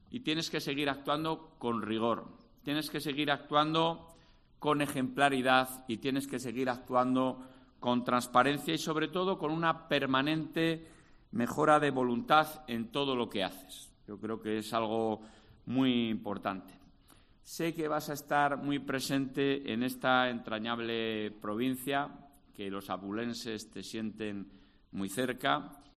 Alfonso Fernández Mañueco. Toma posesión delegado territorial Ávila